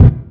SouthSide Kick Edited (49).wav